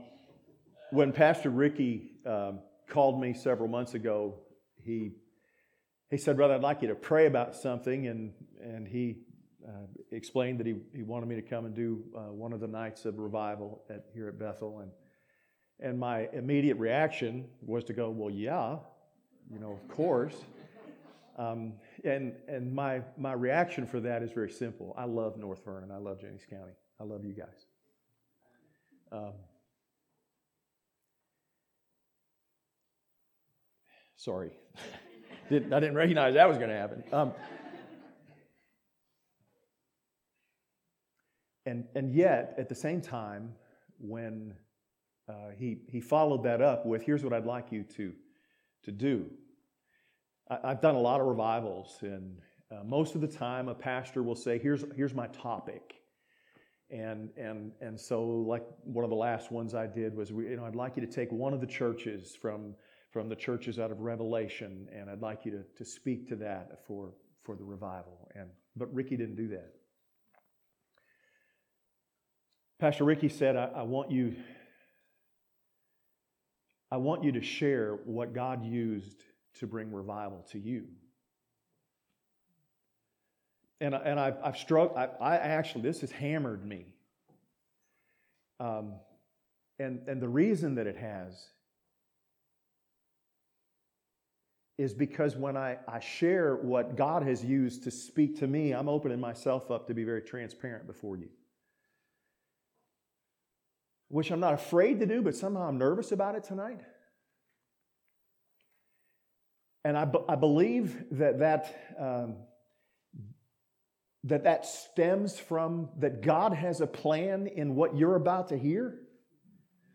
This Sunday morning sermon was recorded on October 7, 2018.